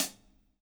DUBHAT-15.wav